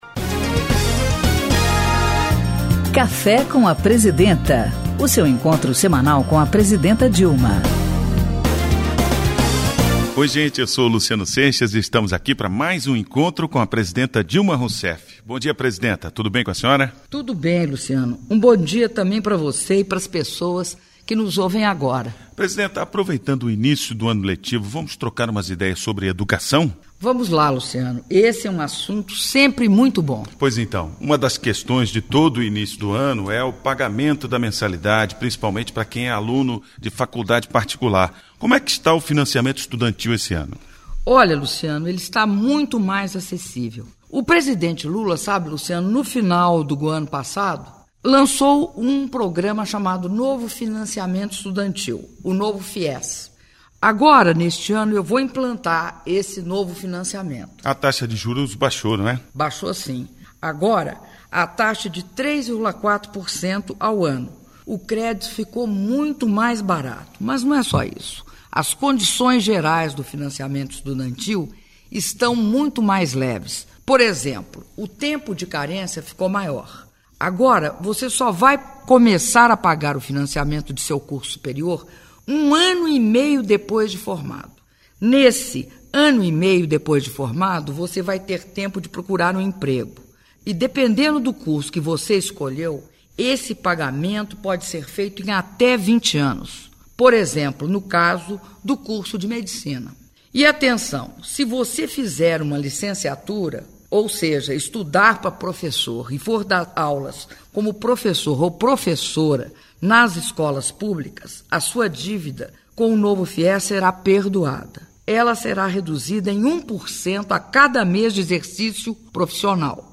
Programa de rádio “Café com a Presidenta”, com a Presidenta da República, Dilma Rousseff